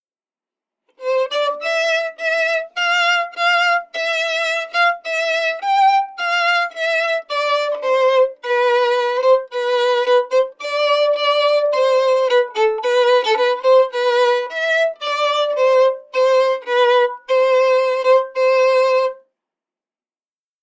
“Rogero,” played quickly/joyfully on the violin